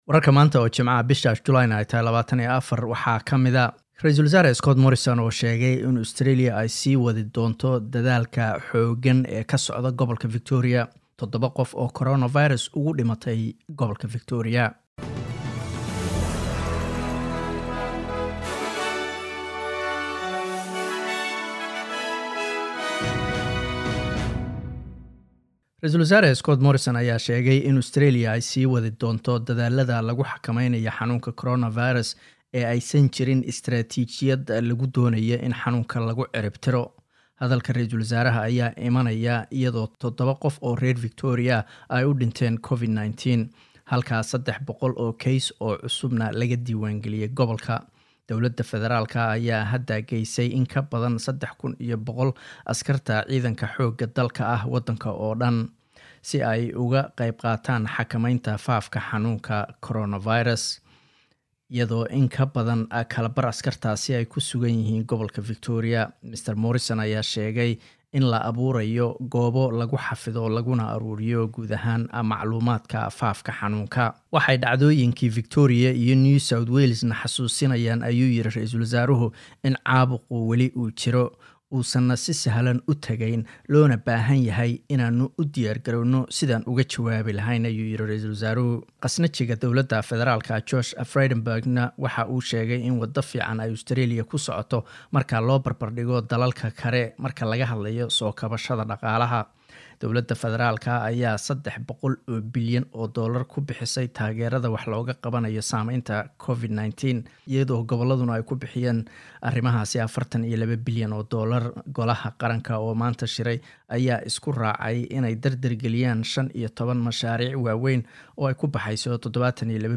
Wararka SBS Somali Jimco 24 July